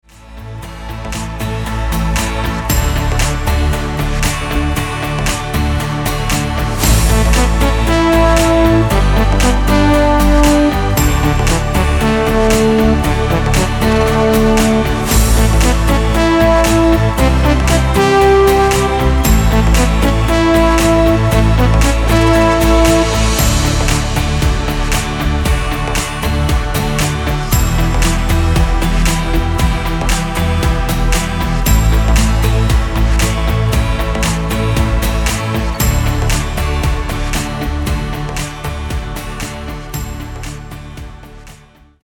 Tonart: D Dur Karaoke Version ohne Chor